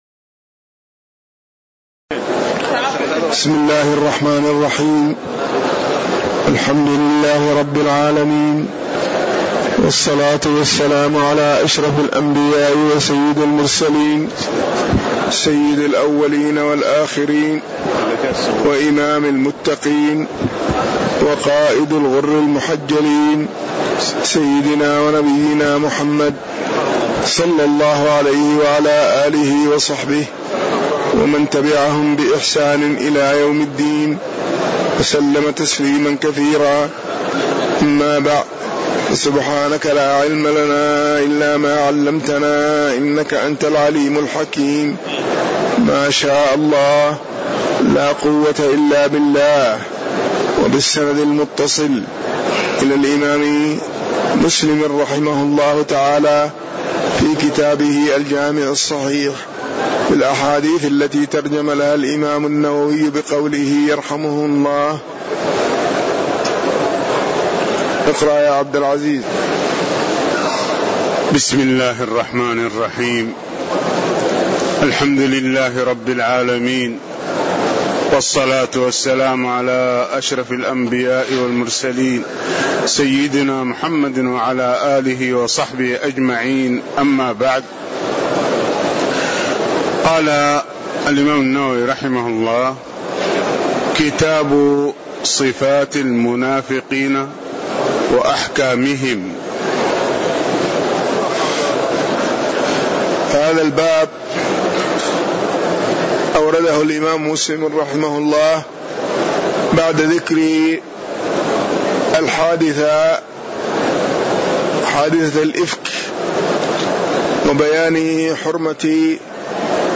تاريخ النشر ٢٤ جمادى الأولى ١٤٣٨ هـ المكان: المسجد النبوي الشيخ